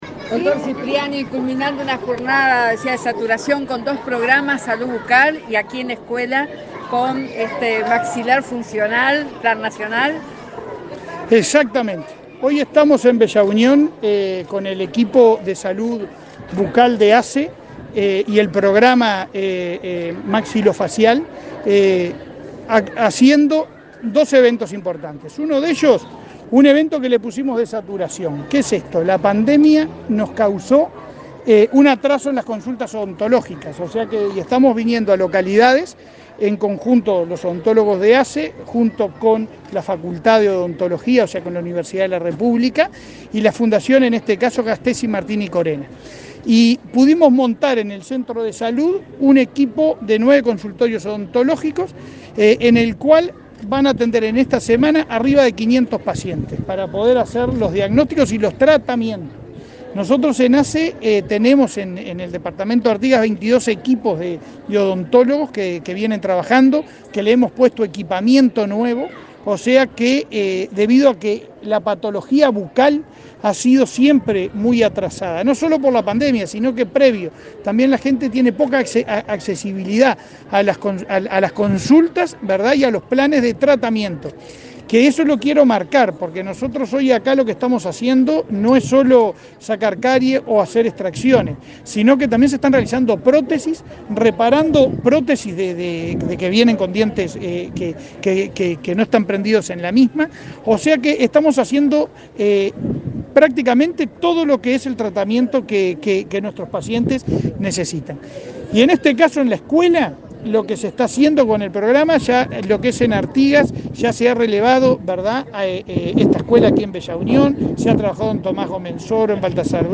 Declaraciones al presidente de ASSE, Leonardo Cipriani
Declaraciones al presidente de ASSE, Leonardo Cipriani 26/04/2022 Compartir Facebook X Copiar enlace WhatsApp LinkedIn Tras participar en el lanzamiento del Plan de Intervención de Salud Bucal y la presentación de avances del Plan de Ortopedia Maxilar Funcional, en Artigas, este 26 de abril, el presidente de la Administración de los Servicios de Salud del Estado (ASSE) efectuó declaraciones a la prensa.